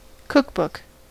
cookbook: Wikimedia Commons US English Pronunciations
En-us-cookbook.WAV